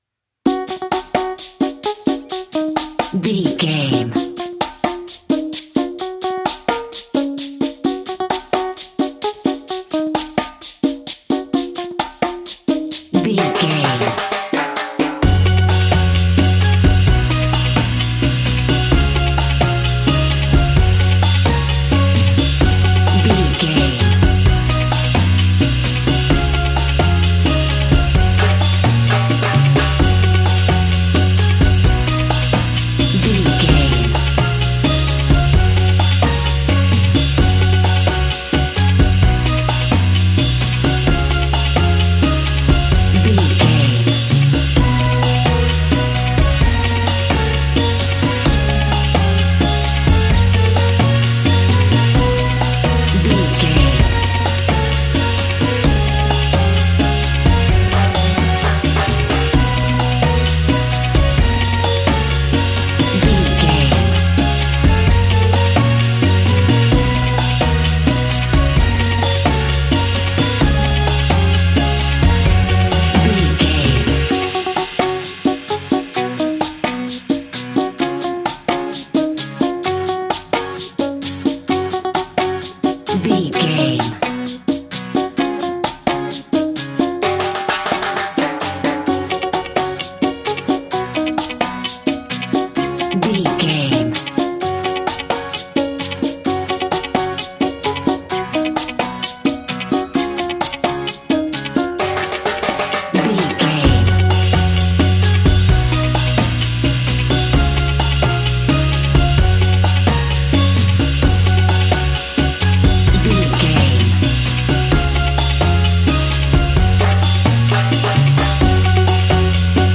Uplifting
Ionian/Major
F#
steelpan
drums
percussion
bass
brass
guitar